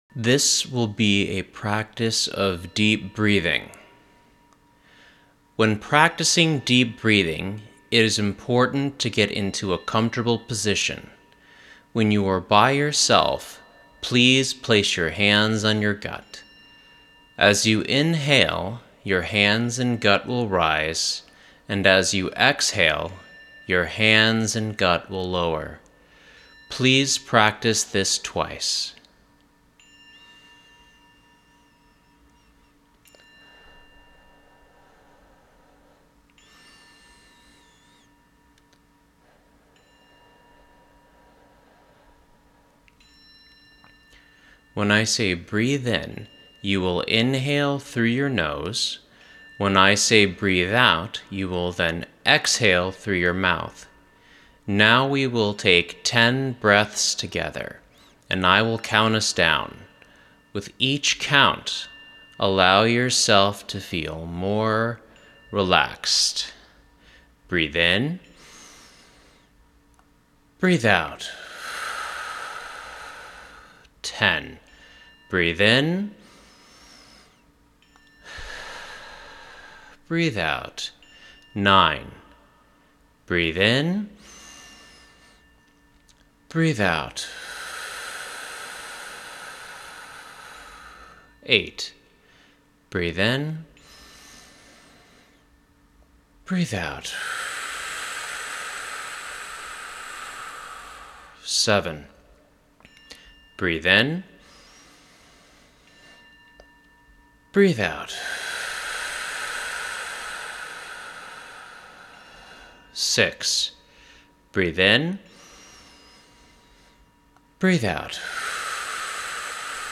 deep-breathing-practice.mp3